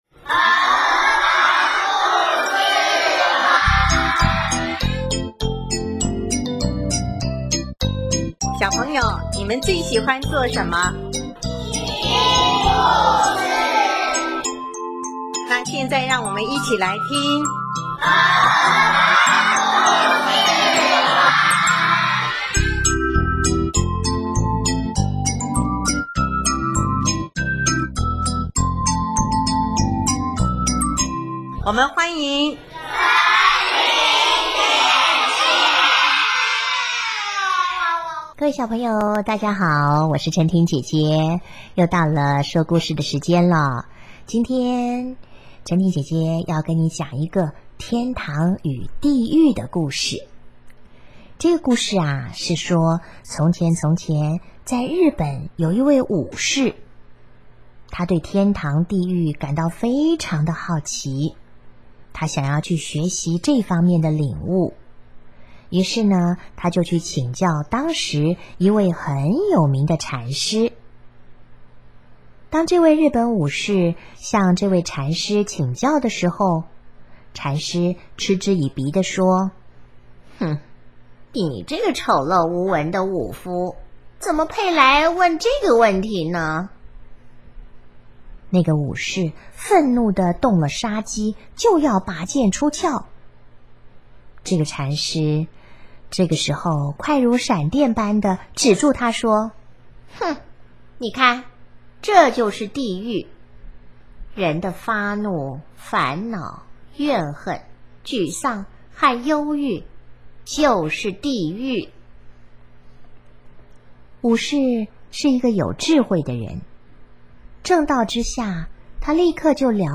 【儿童故事】|天堂与地狱的故事